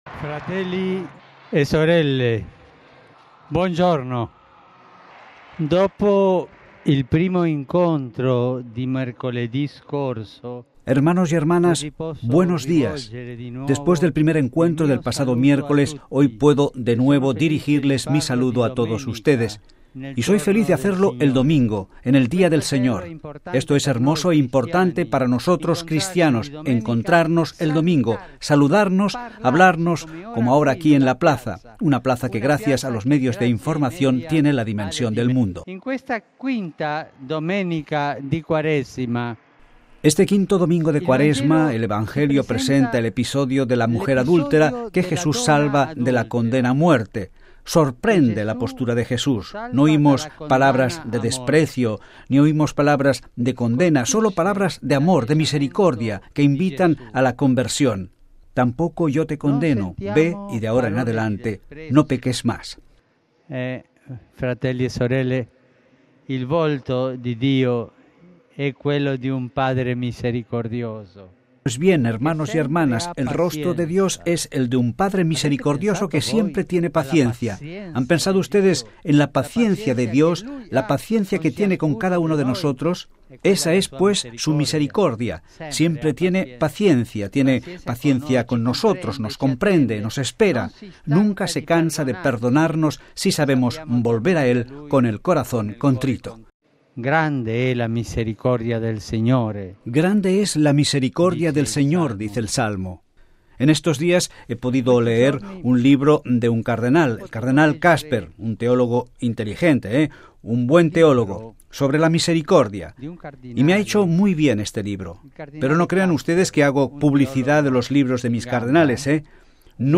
(RV).- El Papa Francisco en su primer Ángelus ante más de 150 mil fieles ha dicho que Dios "jamás se cansa de perdonar a los hombres" y que si Dios no perdonara, el mundo "no existiría". Desde la ventana de su apartamento, el pontífice agregó que son los hombres los que se cansan de pedir el perdón a Dios.